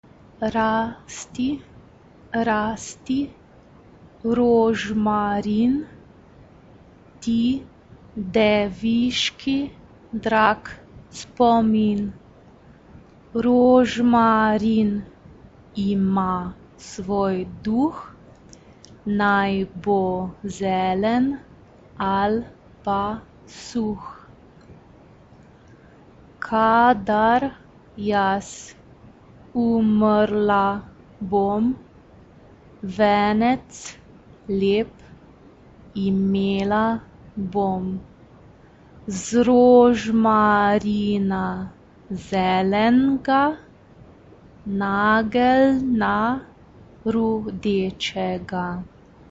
SAB (3 voix mixtes) ; Partition complète.
Genre-Style-Forme : Profane ; Traditionnel ; Folklore Caractère de la pièce : intime Type de choeur : SAB (3 voix mixtes )
Tonalité : ré majeur